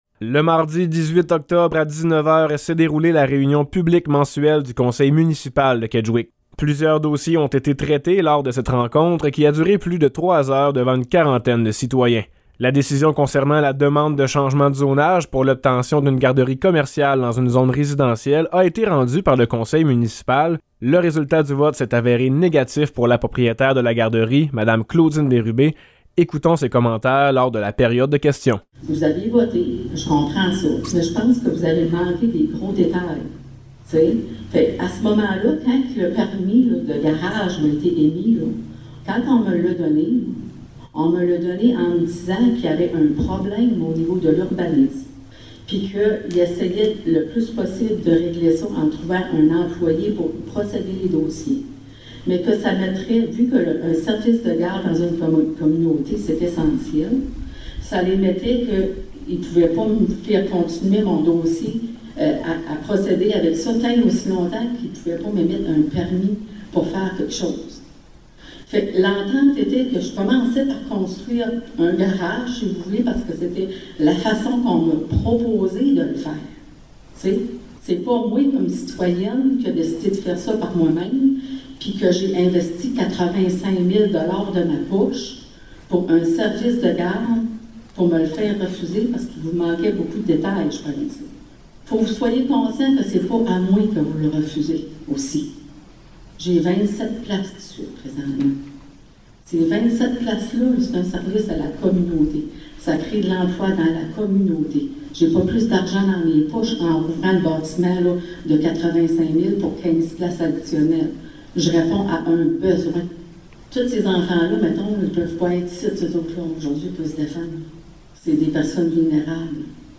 Reportage
reportage-reunion-kedgwick-18oct.mp3